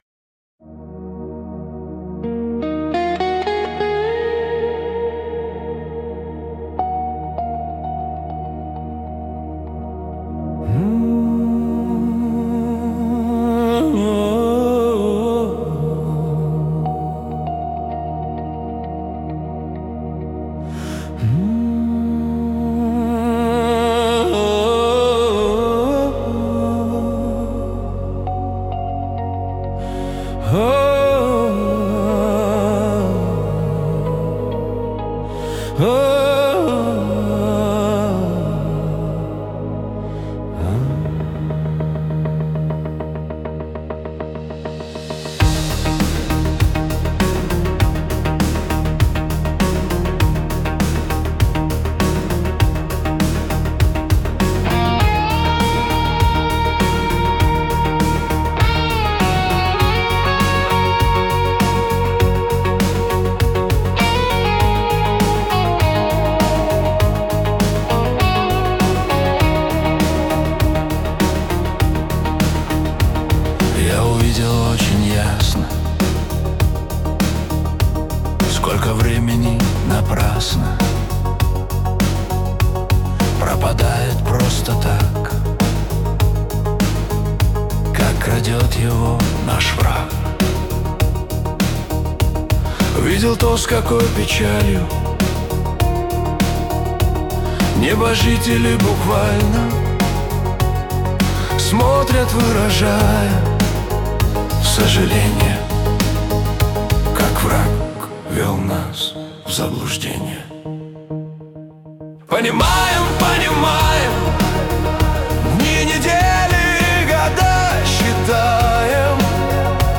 песня ai
117 просмотров 561 прослушиваний 43 скачивания BPM: 103